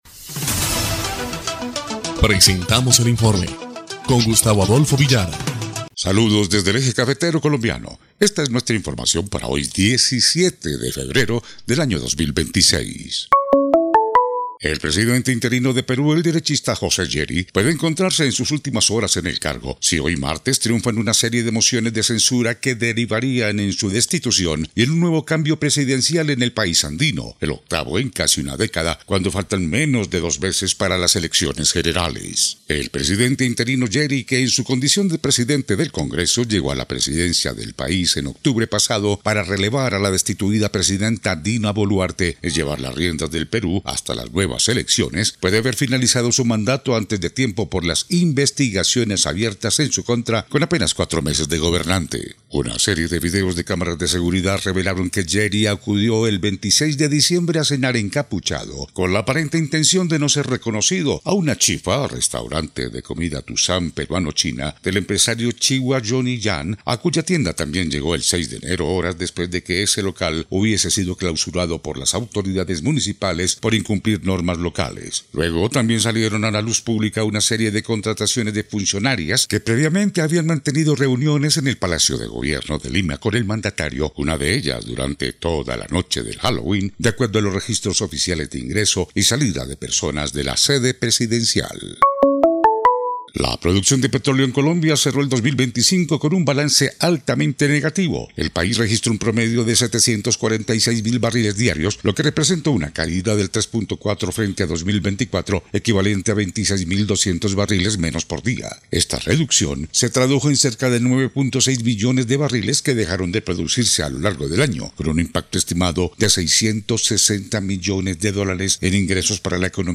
EL INFORME 1° Clip de Noticias del 17 de febrero de 2026